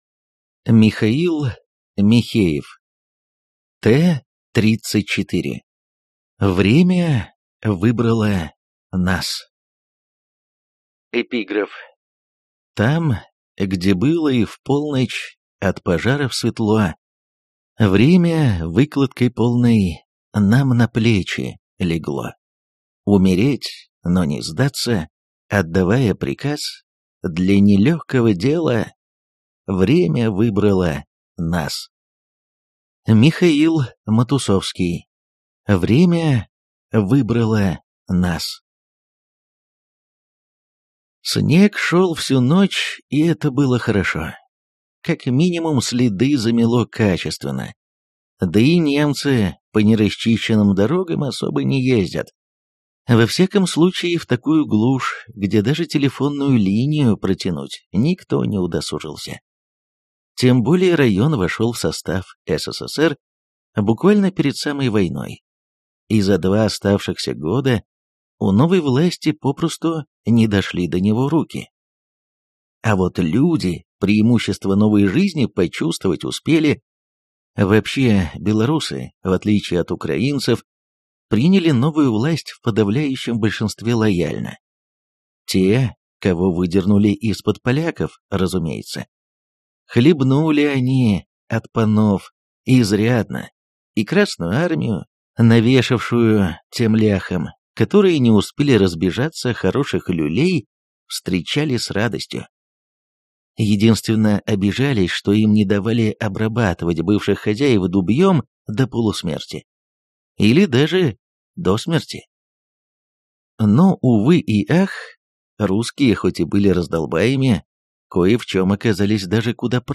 Аудиокнига Т-34. Время выбрало нас | Библиотека аудиокниг